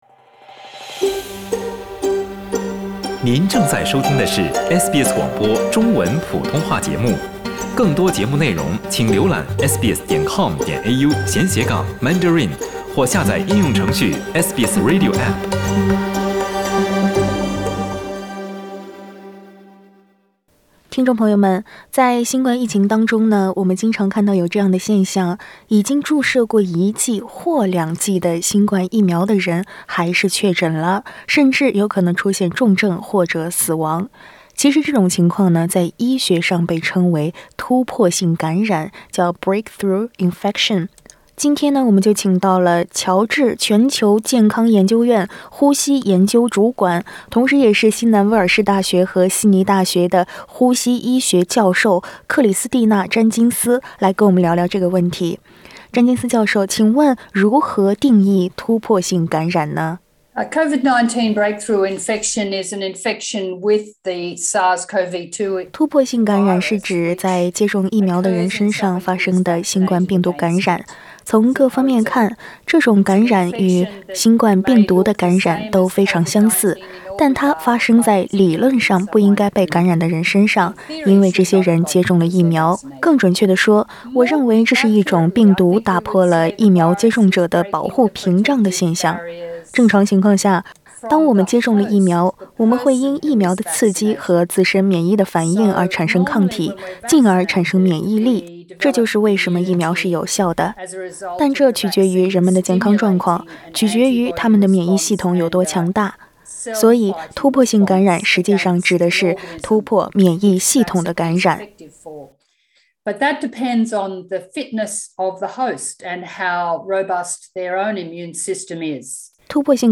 突破性感染是什么？它为什么会发生？（请点击图片收听采访）